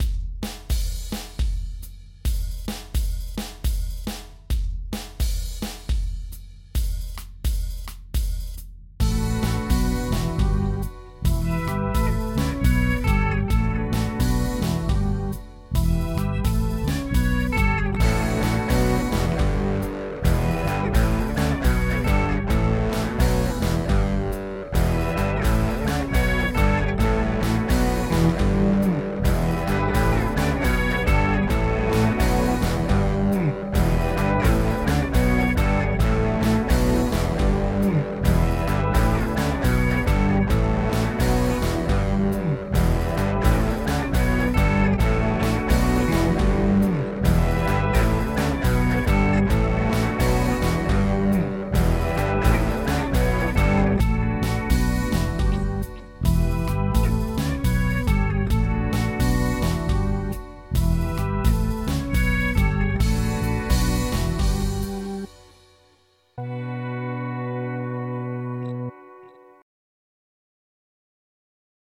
Αὐτὴ ἡ διάρκεια μέτρου καλύπτεται αὐστηρὰ μὲ τὴν χρήση δύο μακρόχρονων καὶ μίας βραχύχρονης ἀξίας (ἄθροισμα τῶν δύο προηγούμενων ρυθμῶν).
Τοὺς ρυθμοὺς αὐτῆς τῆς ὁμάδας θὰ τοὺς ὀνομάσουμε τριγωνικοὺς τριάρι.